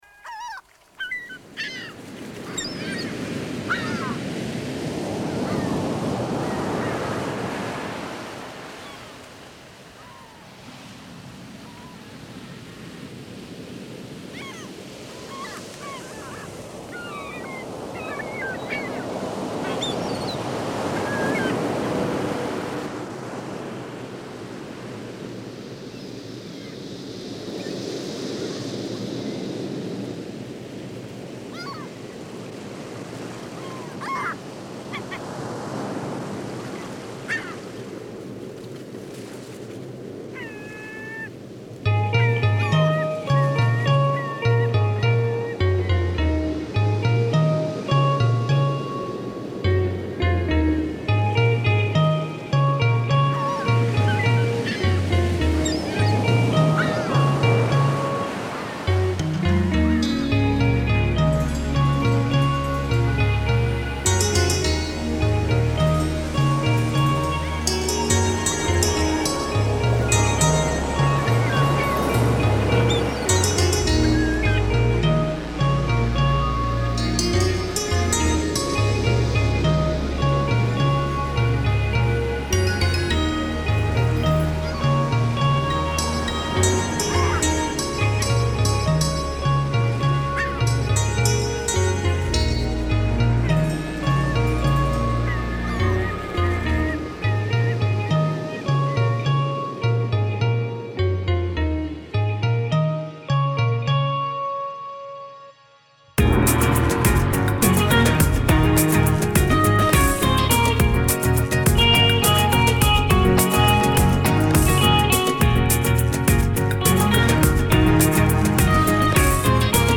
A New Song composed on Ableton Live, exported and imported into Studio One where Bass, Lead Guitar and some editing of drums were done.